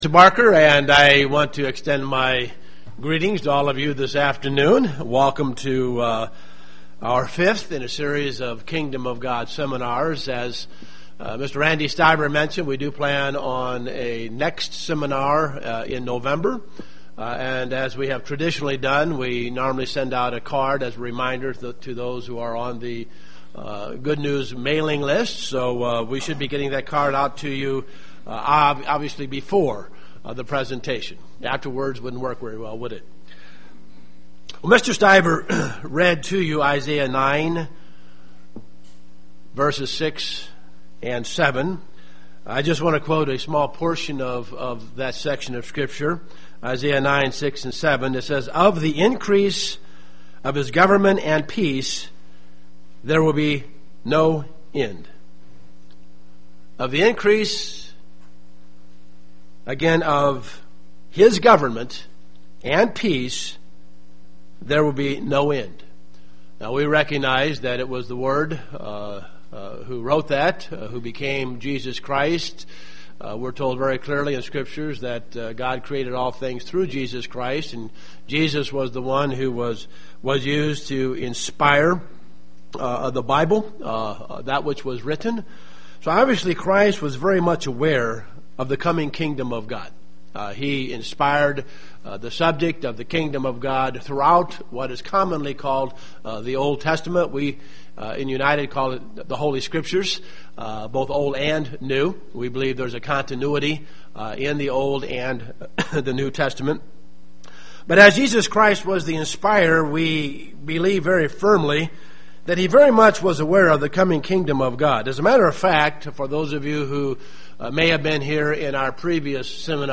Learn more in this Kingdom of God seminar.
Given in Dayton, OH
UCG Sermon Studying the bible?